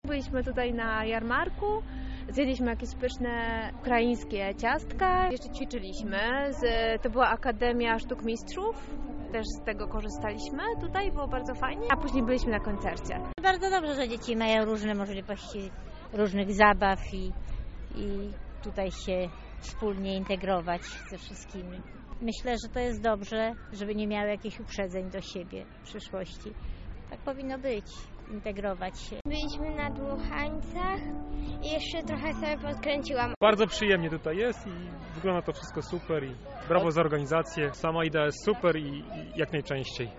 Wydarzenie trwało przez cztery dni na Placu Lecha Kaczyńskiego w Lublinie.